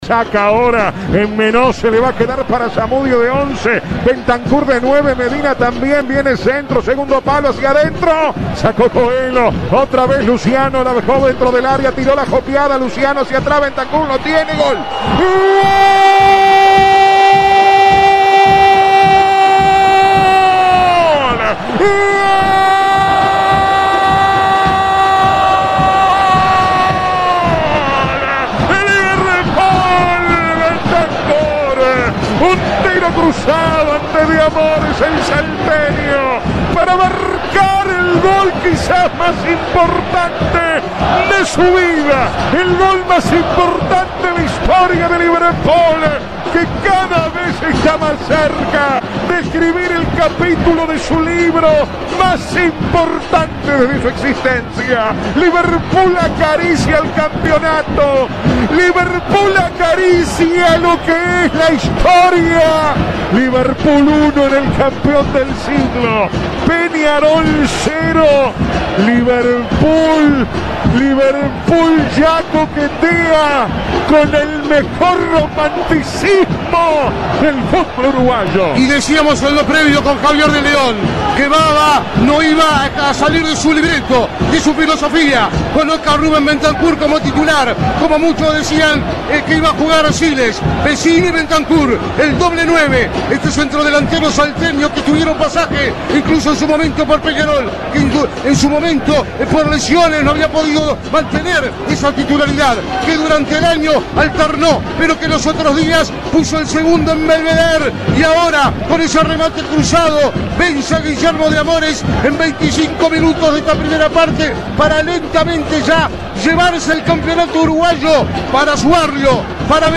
El gol más importante de la historia de Liverpool en la voz del equipo de Vamos que Vamos